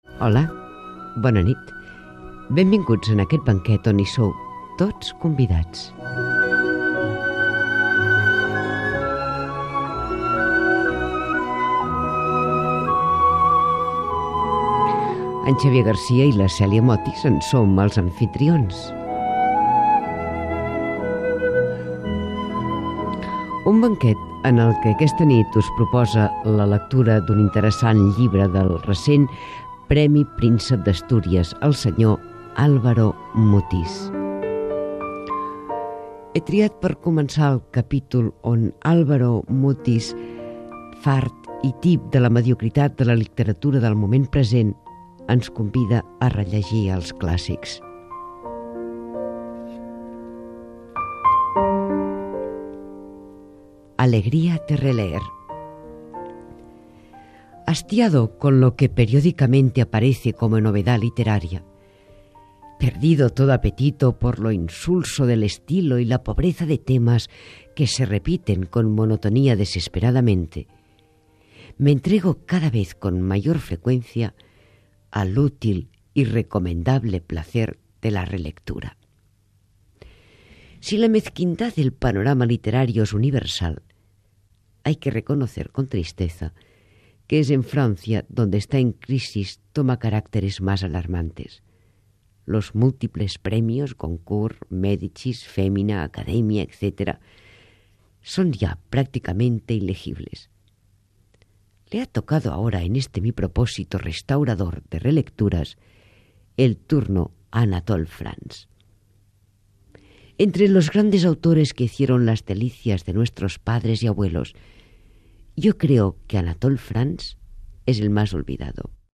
Presentació i lectura d'un fragment de l'obra de l'escriptor Álvaro Mutis
FM